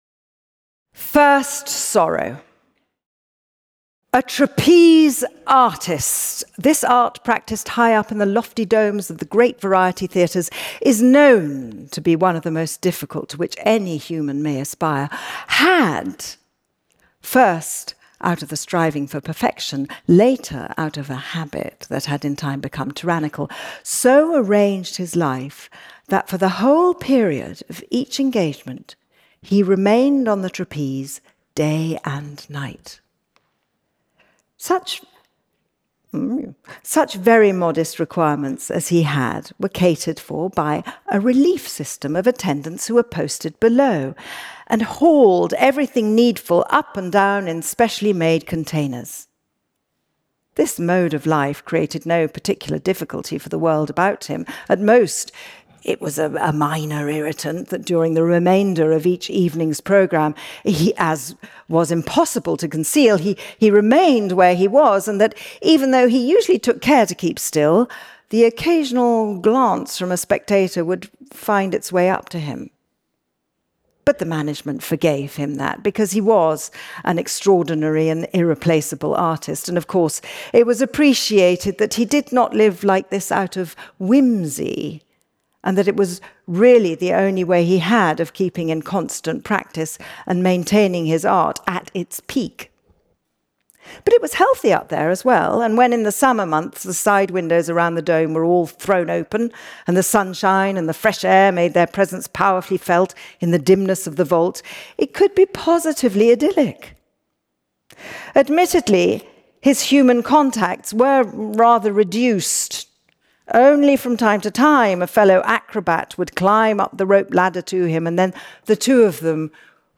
Kristin Scott Thomas Reads Kafka